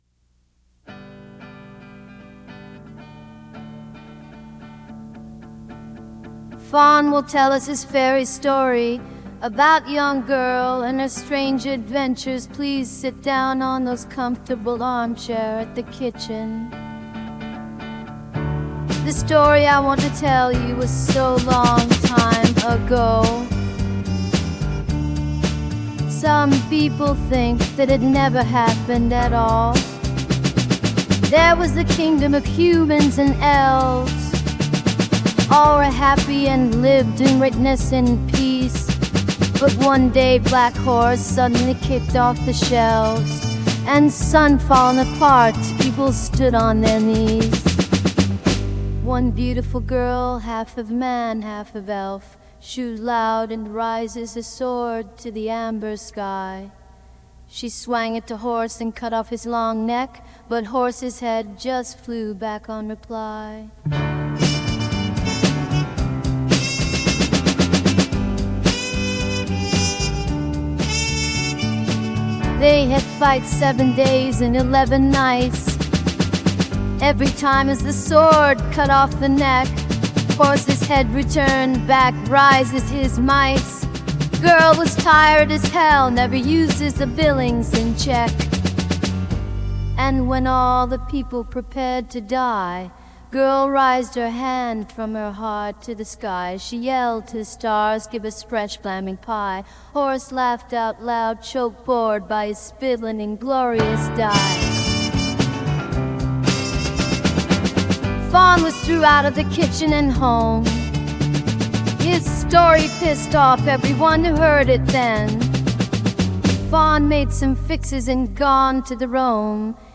• The contest accepts any music made using AI tools (artifical neural networks, GAN etc.)